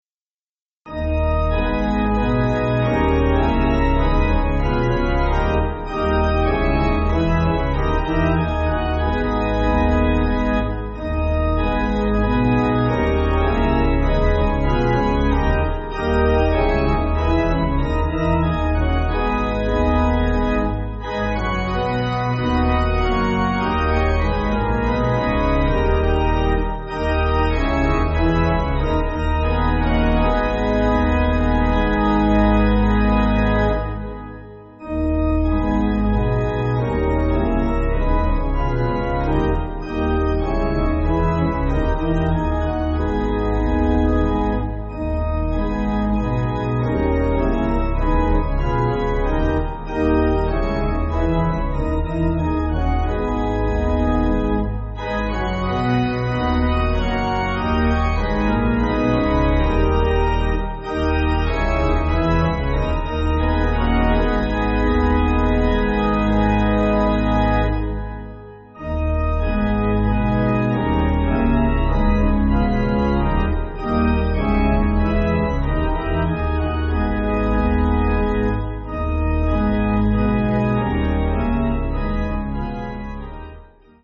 Organ
(CM)   4/G#m